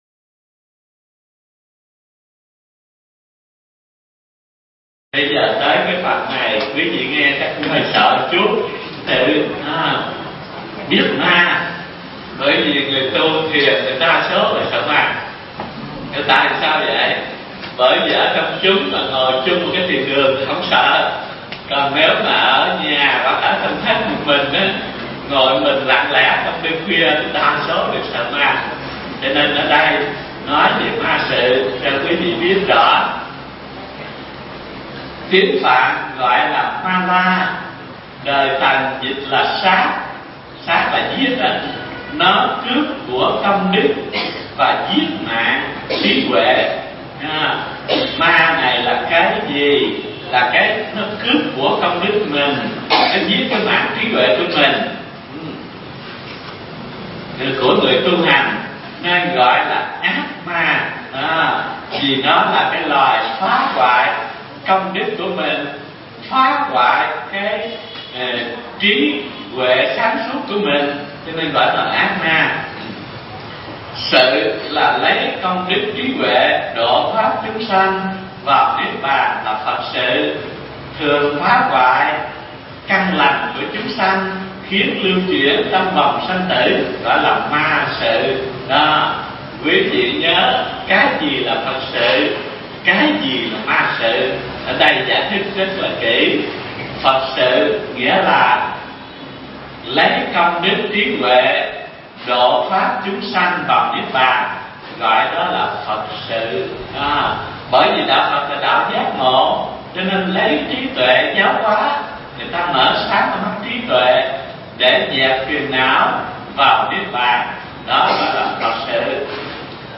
Mp3 Pháp Thoại Thiền Căn Bản 11 – Phần Chánh Tông – Hiểu Biết Ma Sự – Thầy Thích Thanh Từ giảng tại chùa Ấn Quang từ ngày 24 tháng 10 năm 1998 đến ngày 24 tháng 2 năm 2001